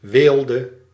Ääntäminen
IPA : /ˈlʌk.ʃə.ɹi/